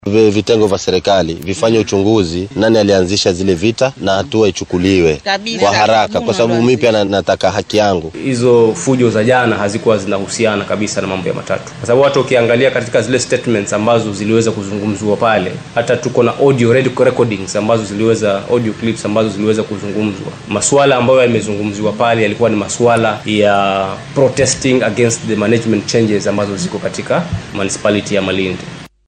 Qaar ka mid ah shakhsiyaadkii waxyeellada soo gaartay ayaa warbaahinta u warramay